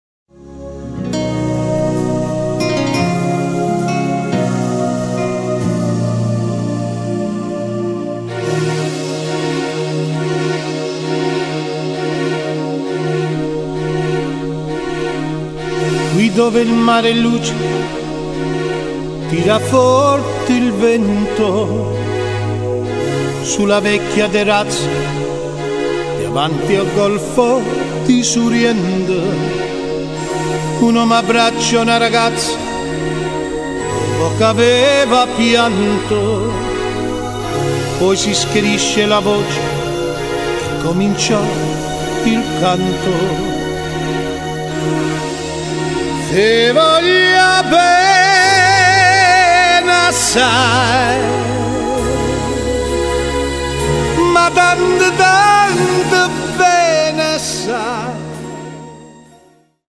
the extraordinary voice and mellow stylings